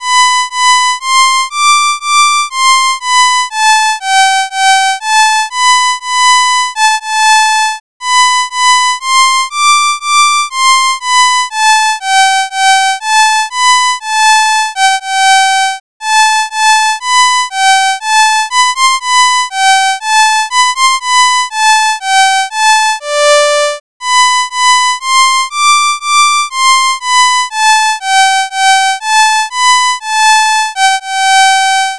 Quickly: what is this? Instrument: violin.